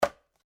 Golpe de piedra contra una calabaza
golpe
Sonidos: Acciones humanas